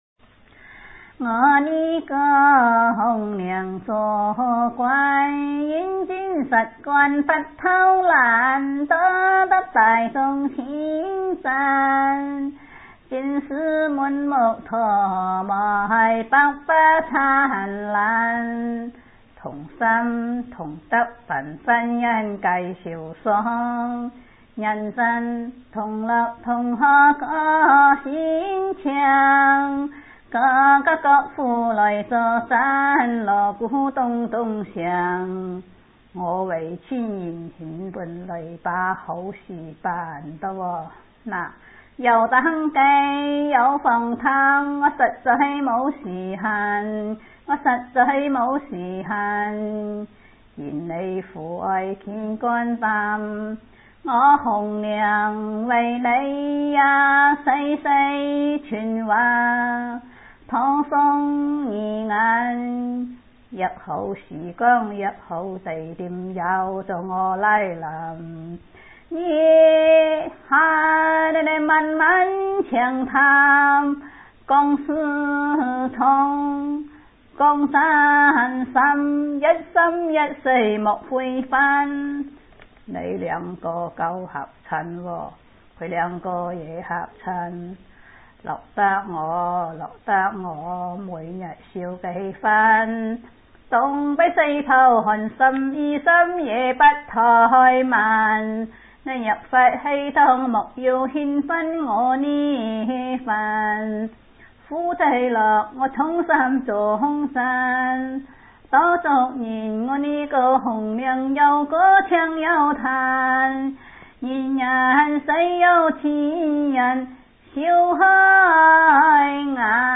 故意唱得極為難聽，這種風格叫做‘瞎嚎’。
清唱瞎嚎